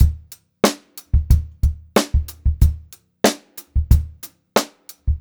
92ST2BEAT2-R.wav